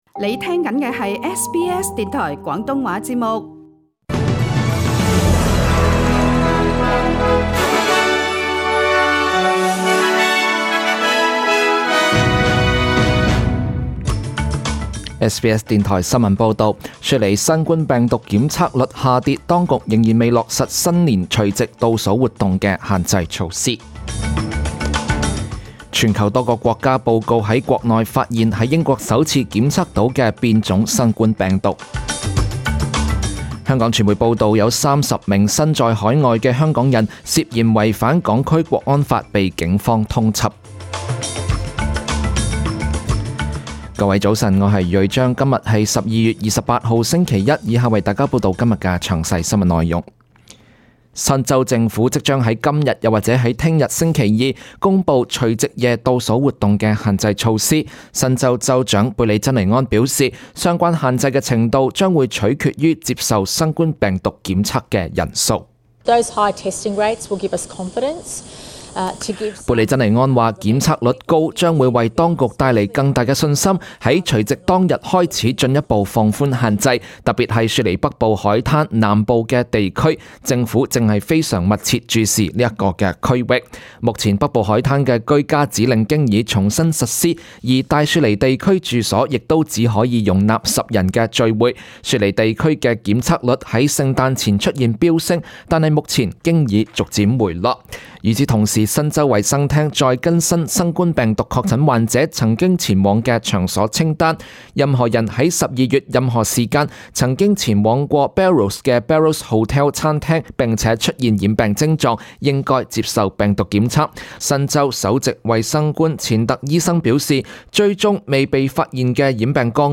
SBS中文新闻 （十二月二十八日）
SBS 廣東話節目中文新聞 Source: SBS Cantonese